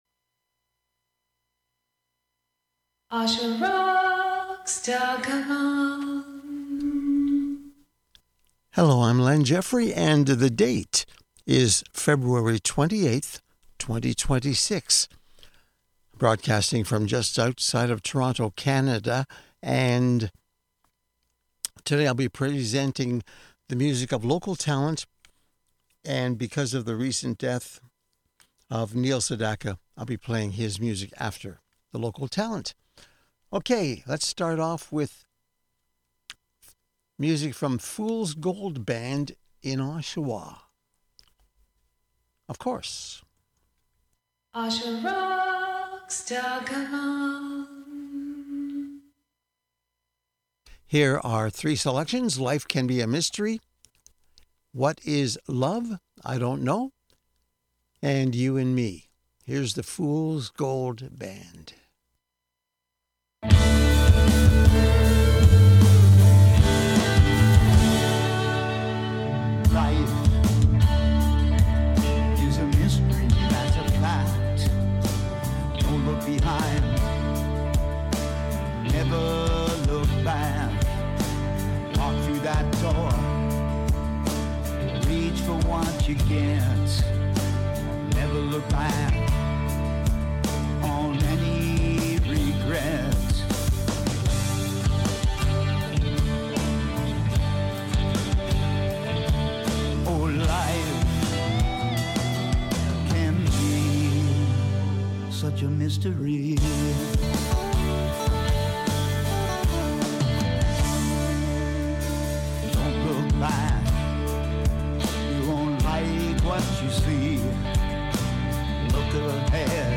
[Please forgive me for losing it early on in this archive] Enjoy.